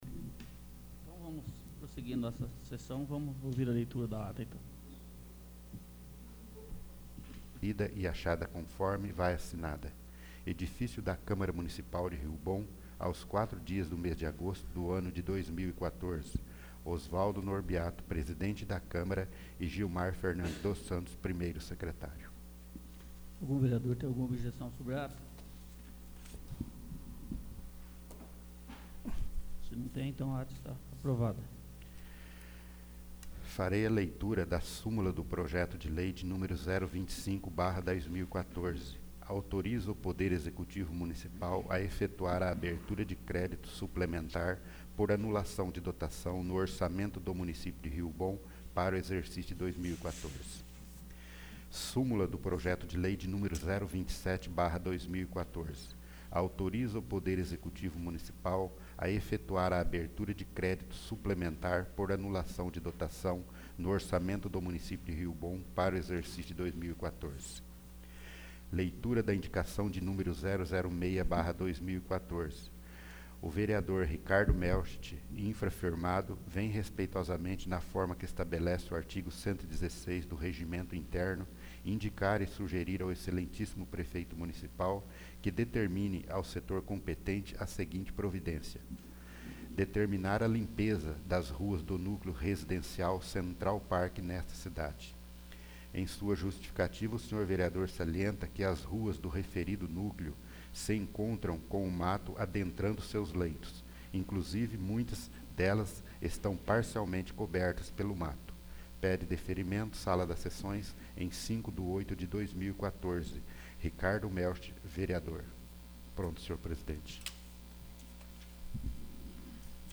22º. Sessão Ordinária